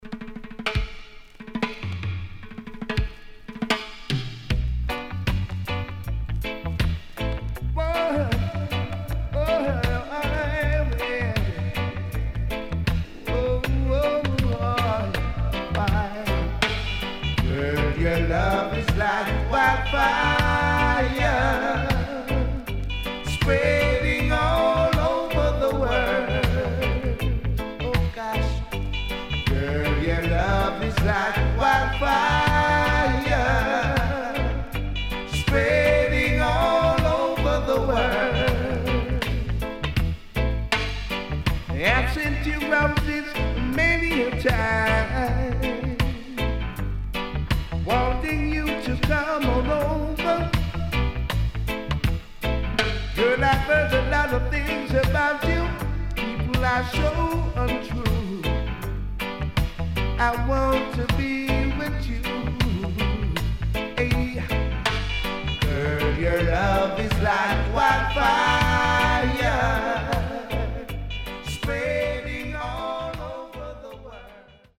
HOME > LP [DANCEHALL]
SIDE A:少しチリノイズ、プチノイズ入ります。